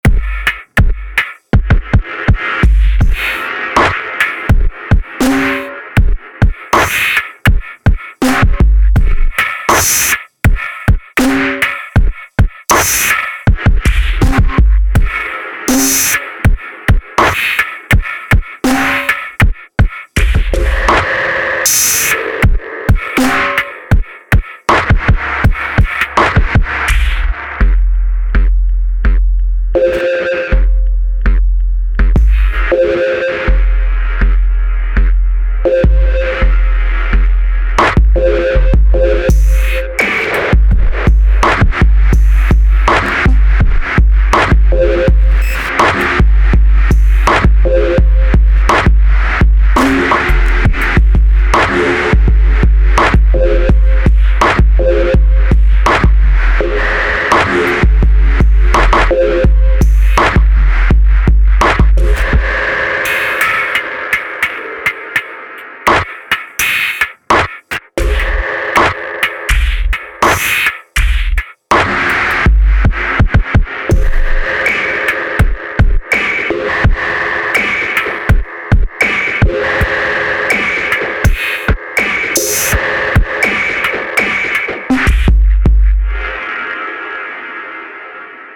Минимал. Хопы соул фанки джази, ликвид днб, глитч бэйс музыка.
Кроме представленного ритм рисунки обычно напоминают фанк брейкс темы в разных bpm Не планировал упарываться в жесткие жанро рамки Мне интересны грувы вайбы.